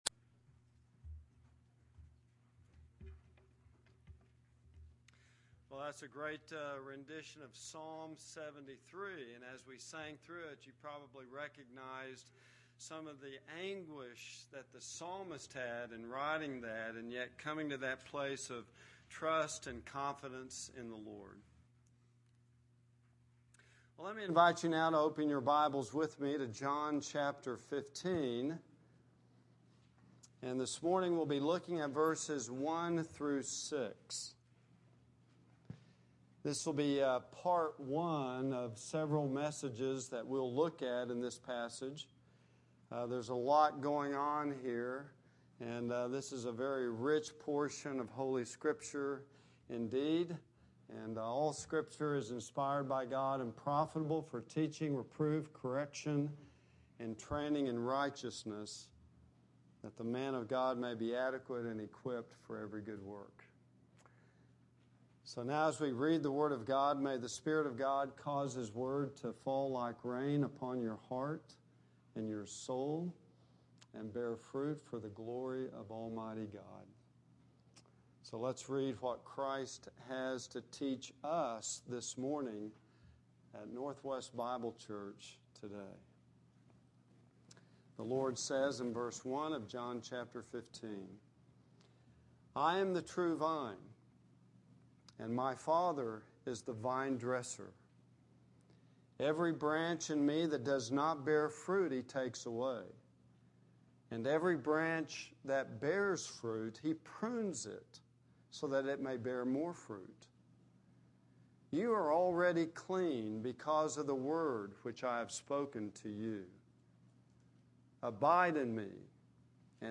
Ruth 1:6-18 Service Type: Sunday AM